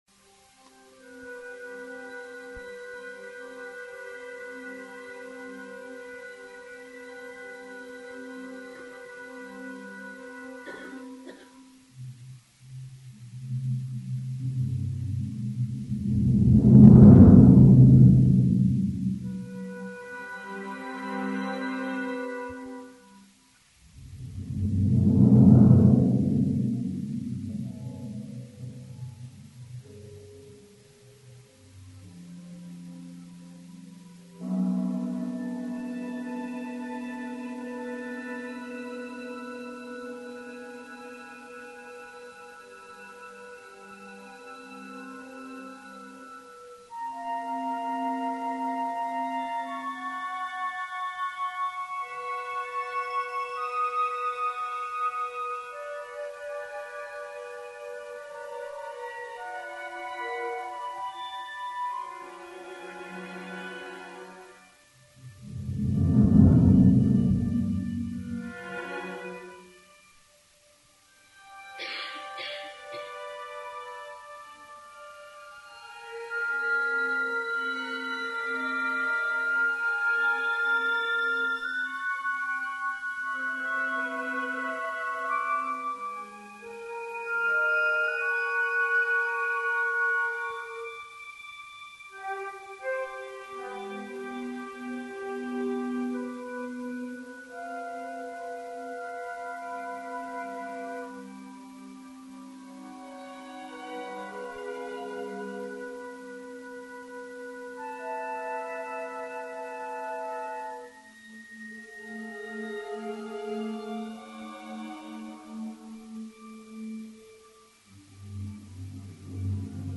orchestral work